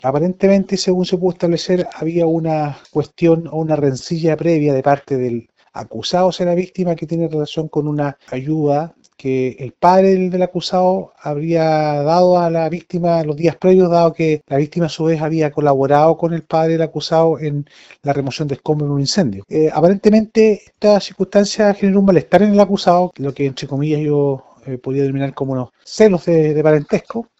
cu-sentencia-2-fiscal.mp3